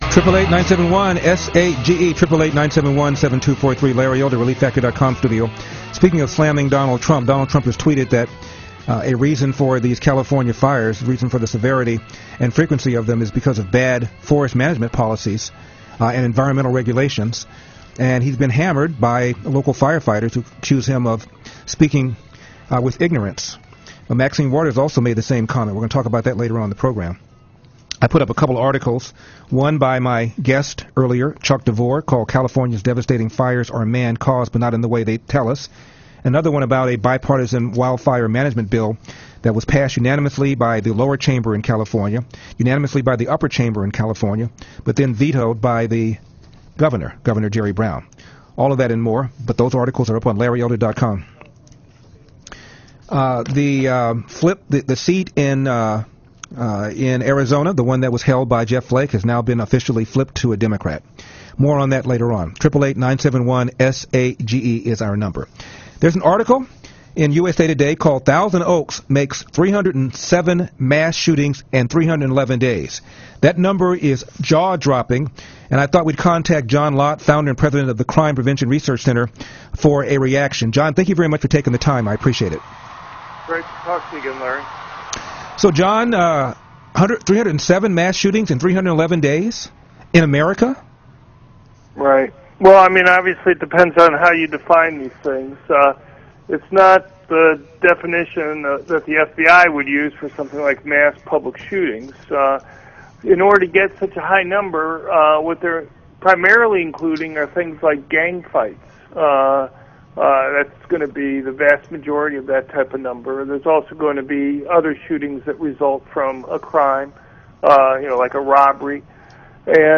John Lott talked to Larry Elder on his national radio show to discuss the California bar shooting, gun-free zones, and media bias.